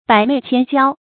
百媚千嬌 注音： ㄅㄞˇ ㄇㄟˋ ㄑㄧㄢ ㄐㄧㄠ 讀音讀法： 意思解釋： 媚、嬌：美好。形容女子姿態美好。